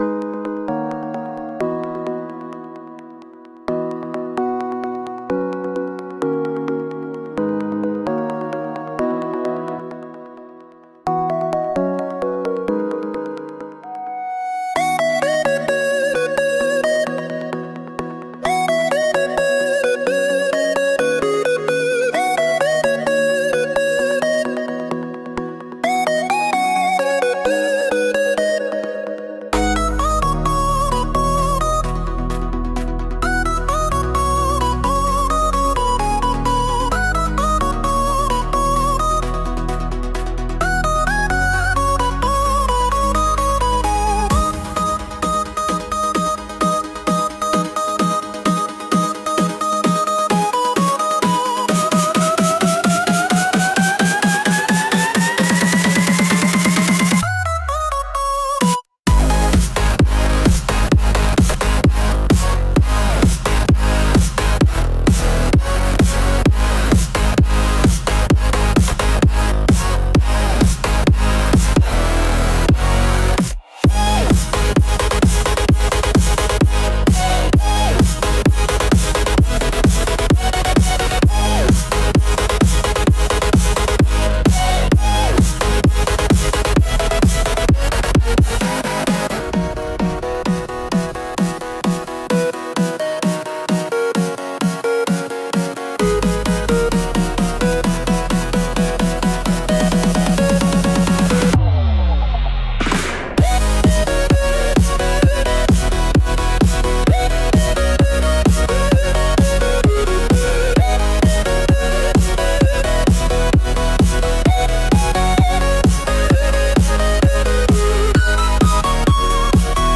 Genre: Dubstep Mood: Futuristic Editor's Choice